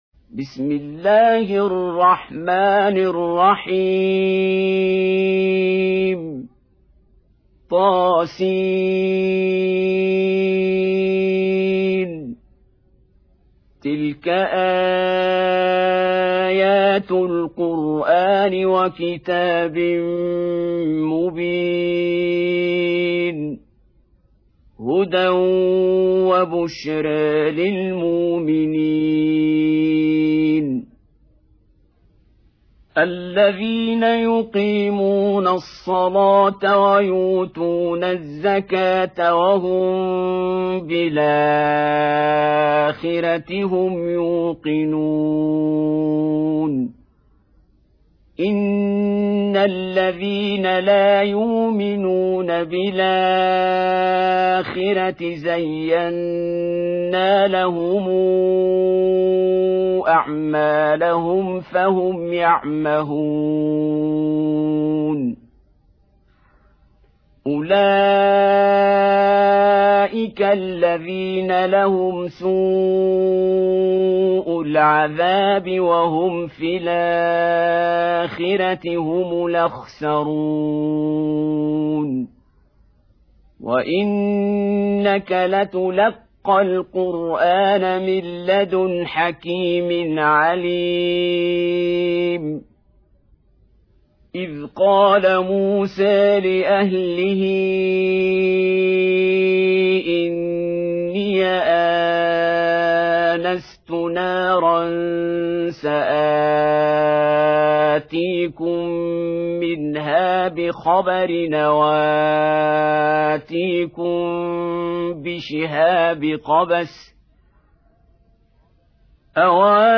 27. Surah An-Naml سورة النّمل Audio Quran Tarteel Recitation
Surah Repeating تكرار السورة Download Surah حمّل السورة Reciting Murattalah Audio for 27.